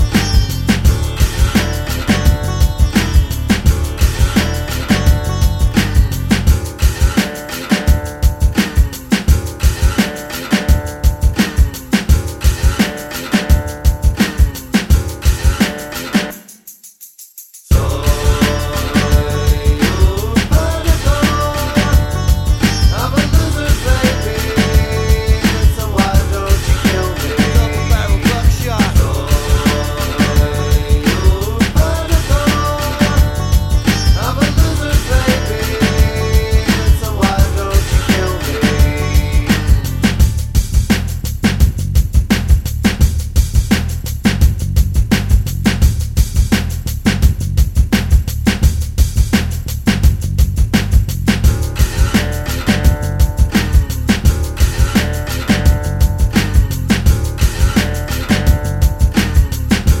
No Backing Vocals Indie / Alternative 3:51 Buy £1.50